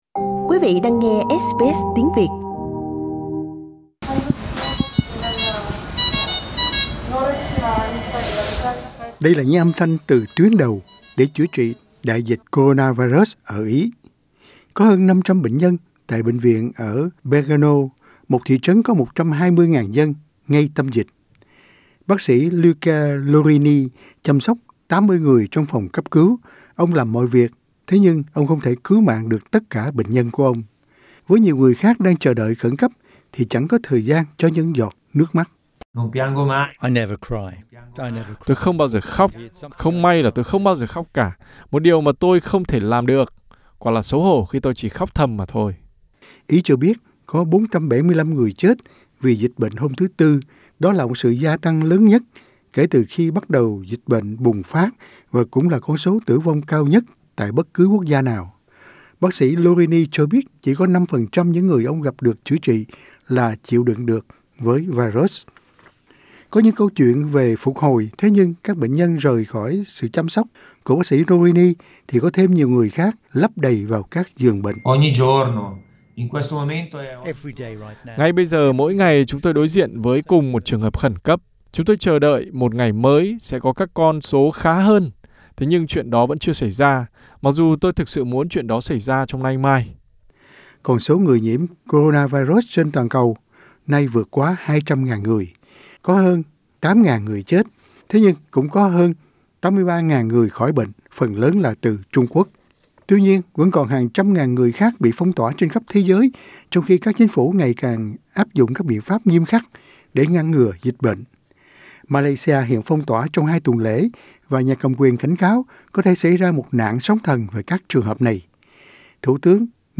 Những âm thanh từ tuyến đầu, để chữa trị đại dịch coronavirus ở Ý.